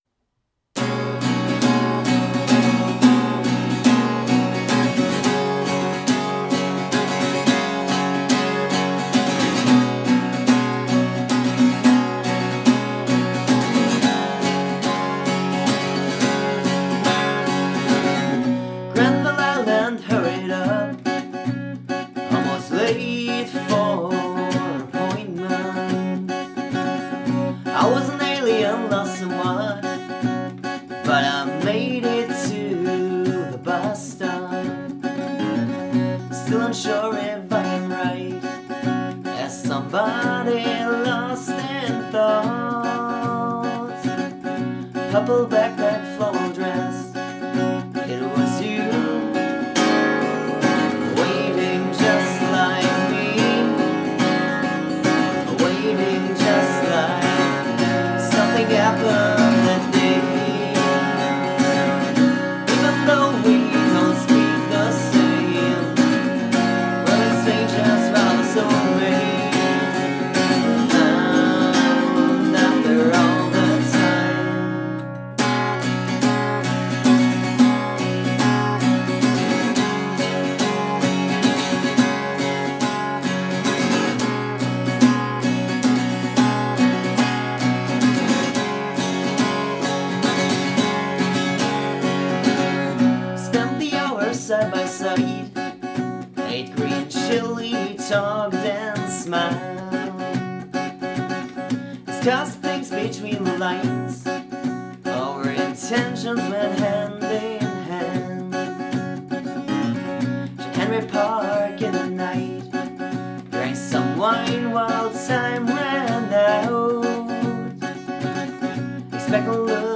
Instruments: Vocals, Acoustic guitar
Effects: Compressor (2:1)